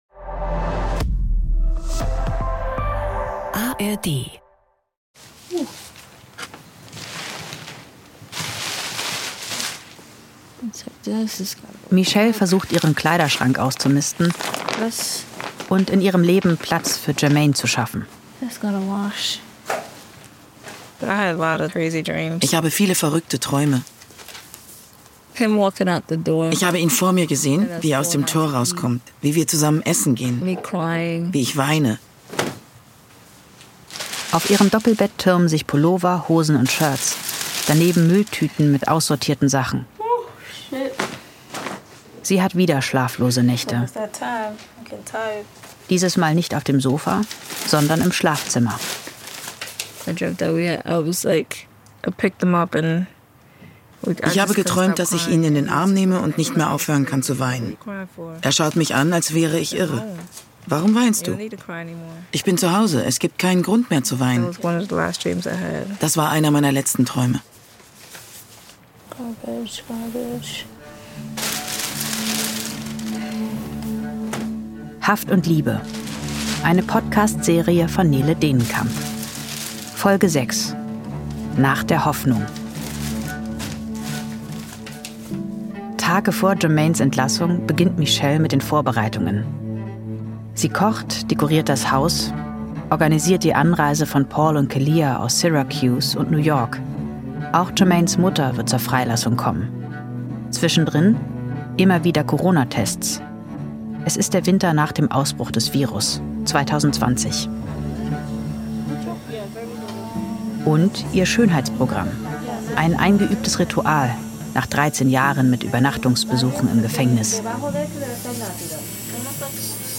eine True Crime-Story aus konsequent weiblicher Perspektive.